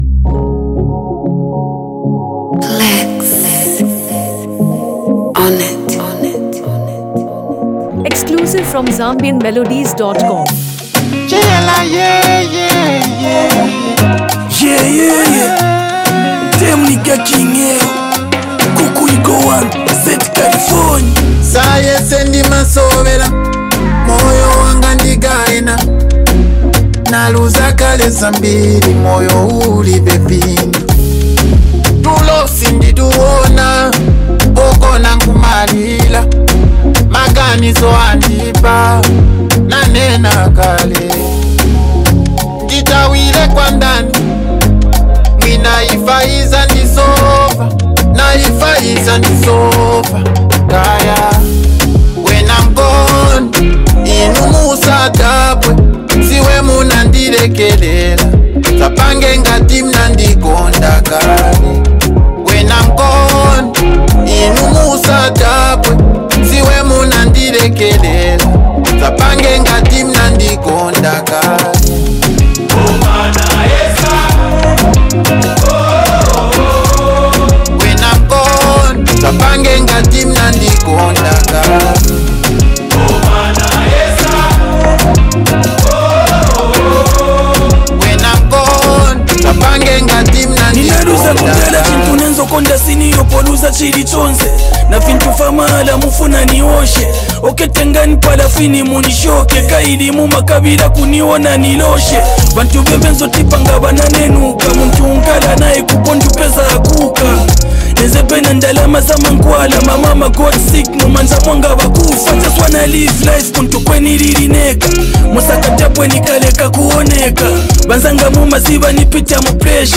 Zambian Music
Uplifting Gospel Mp3